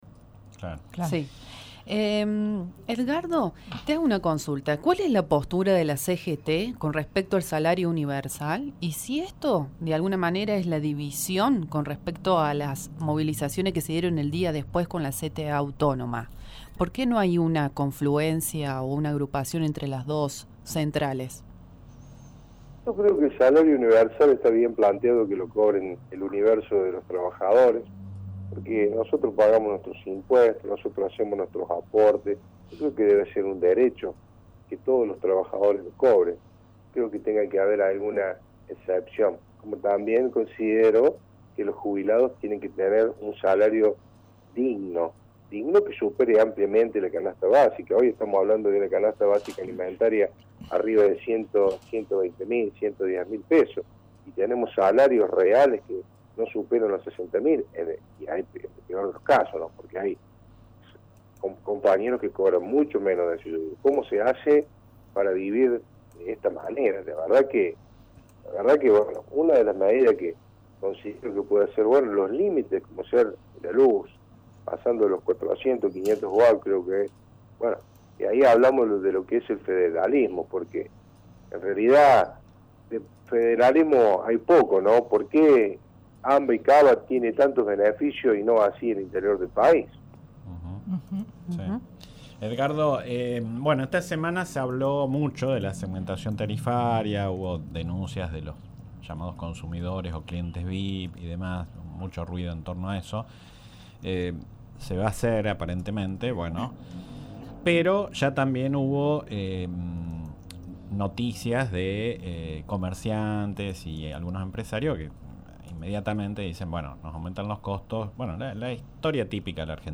Para abordar en profundidad el tema se convocó a un actor partícipe de la vida sindical: Edgardo Garmendia, Secretario General de la CGT Regional Villa María y Presidente del Concejo Deliberante de Villa Nueva.
Entrevista-Garmendia-parte-2.mp3